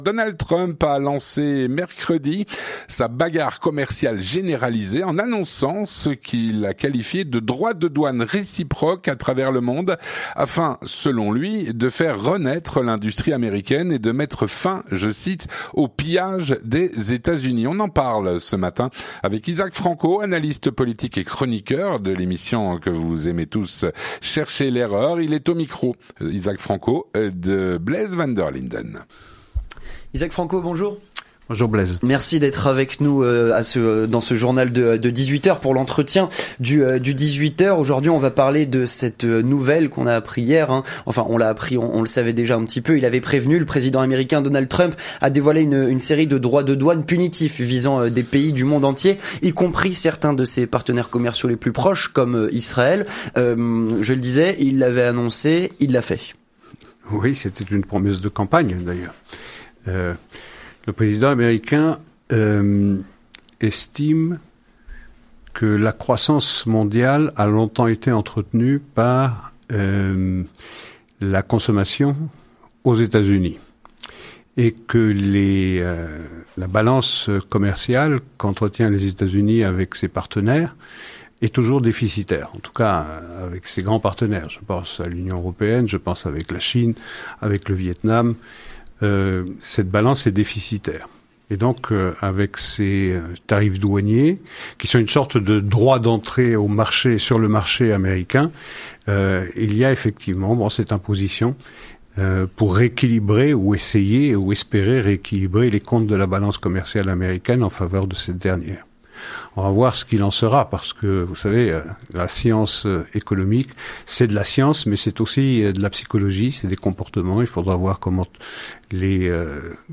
L'interview du 18H - Donald Trump a entériné des "droits de douanes réciproques" à travers le monde.